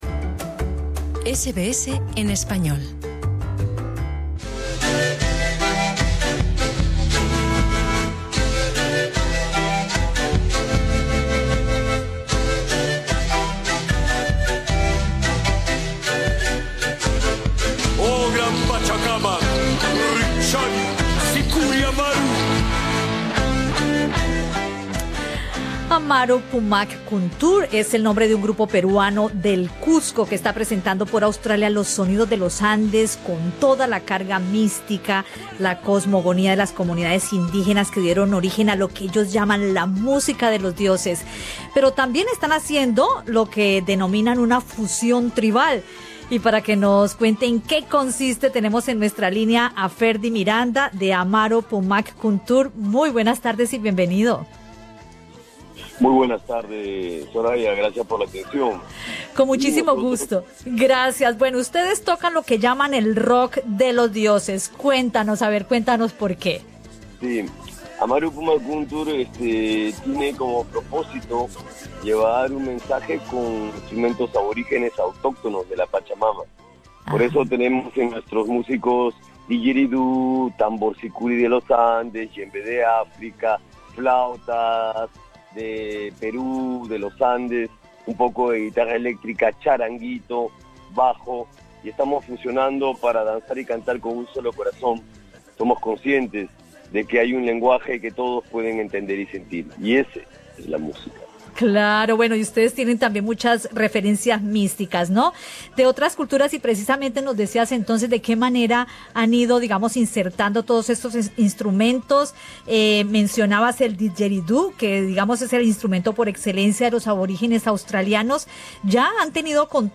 En Radio SBS conversamos con uno de sus integrantes